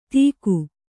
♪ tīku